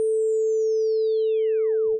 More Warped.wav